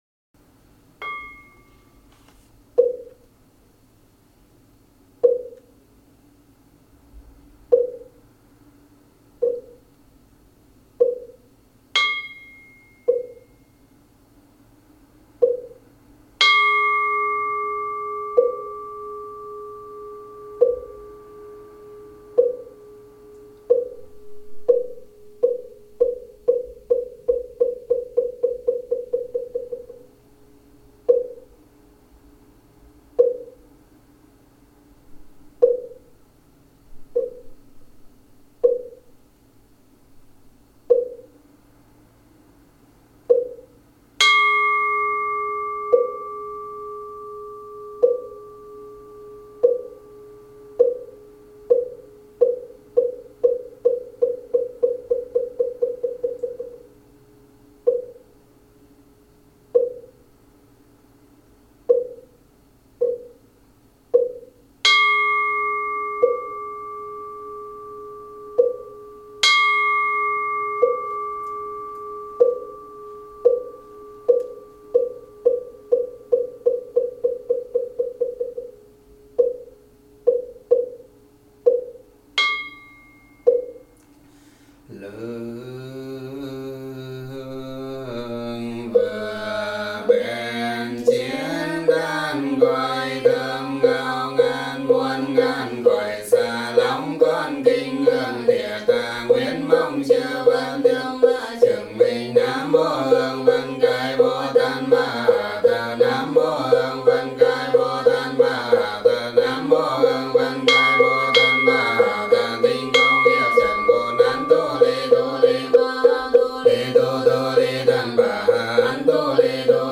3Tung-kinh-dia-tang-Quyen-Ha-Tung-nhanh.mp3